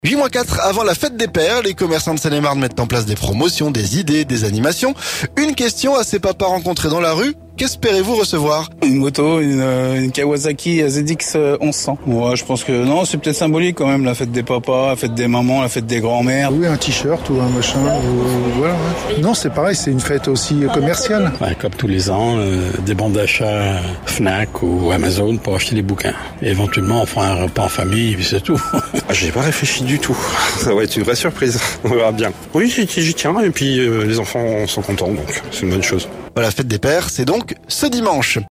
Les commerçants de Seine-et-Marne mettent en place des promotions, des idées et des animations. Une question à ces papas rencontrés dans la rue : qu'espérez-vous recevoir ?